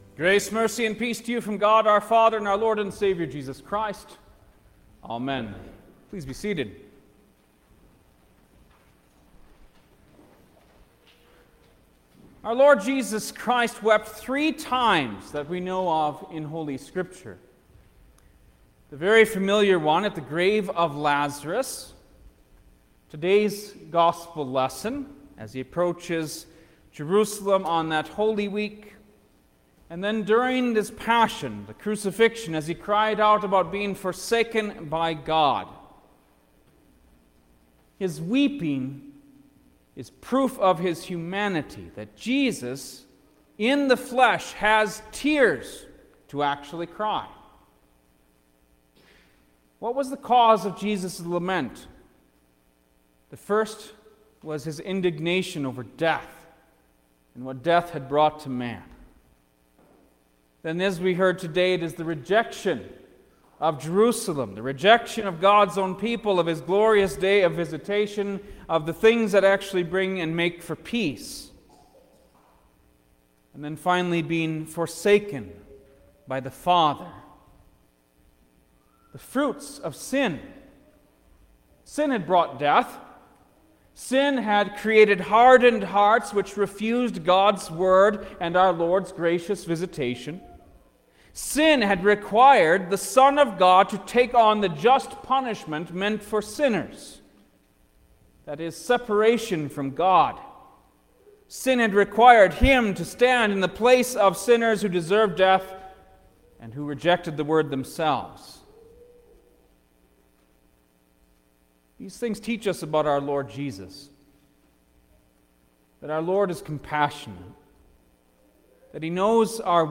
August_8_2021-Tenth-Sunday-After-Trinity_Sermon-Stereo.mp3